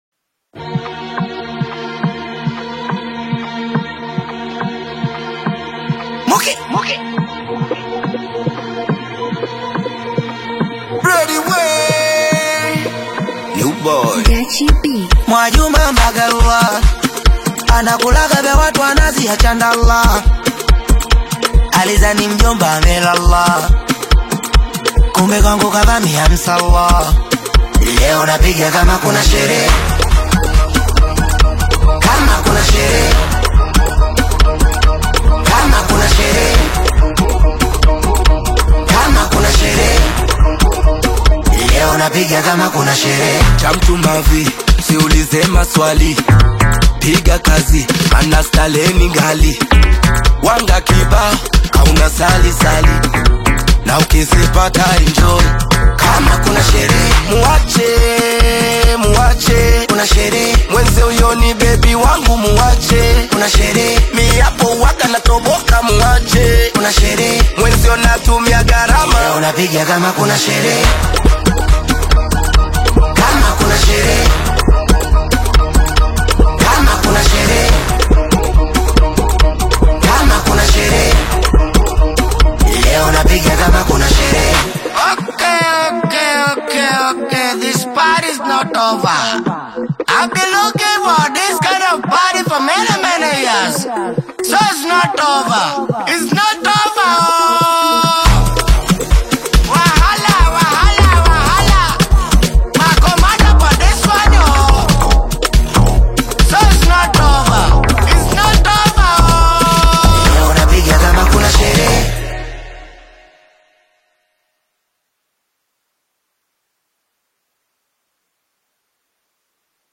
infectious rhythms
vibrant energy